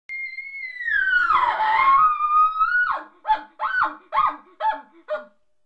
Bull Elk Sounds The Bull Elk make the most magnificent and powerful sounds. This is done to chase off, challenge, and establish dominance over the other Bull Elk.
high_to_low.wma